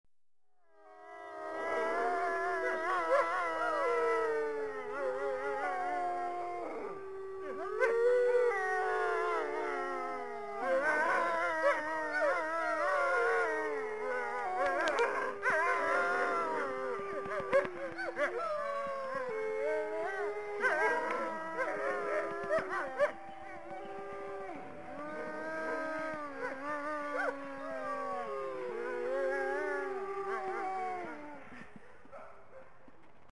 Download Wolves sound effect for free.
Wolves